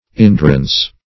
indurance - definition of indurance - synonyms, pronunciation, spelling from Free Dictionary Search Result for " indurance" : The Collaborative International Dictionary of English v.0.48: Indurance \In*dur"ance\, n. [Obs.]